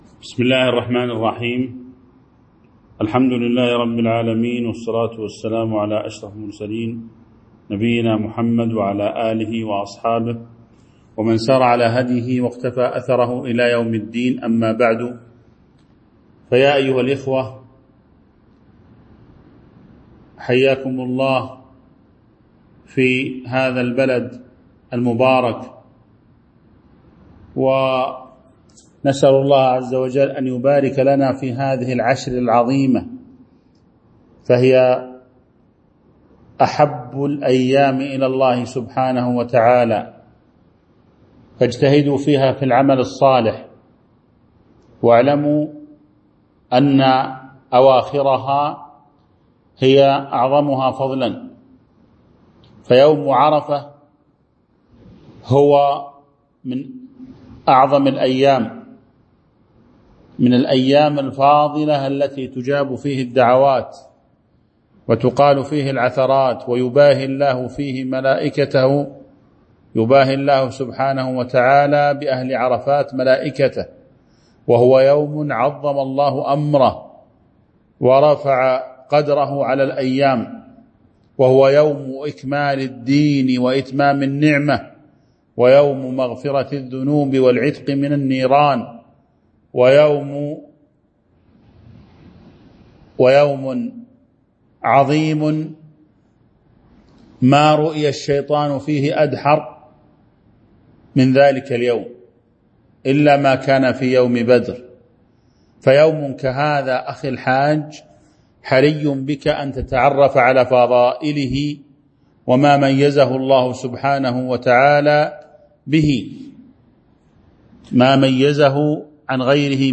تاريخ النشر ٢ ذو الحجة ١٤٤٣ هـ المكان: المسجد النبوي الشيخ